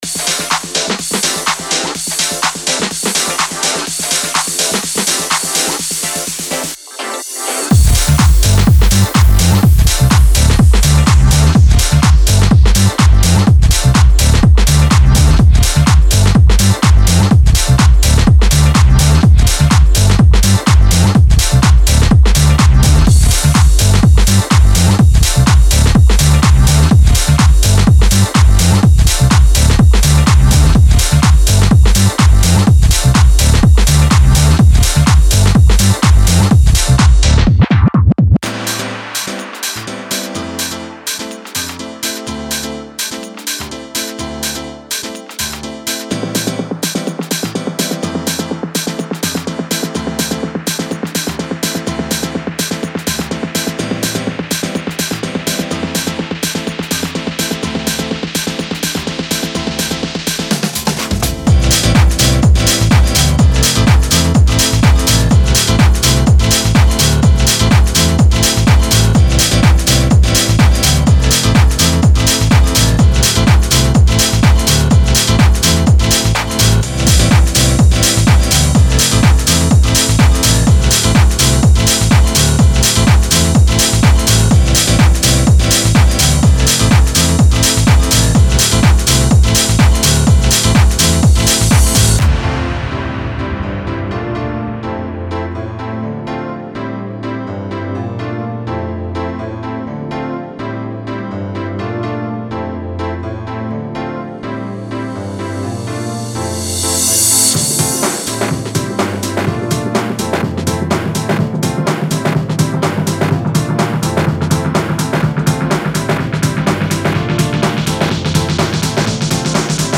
Genre:House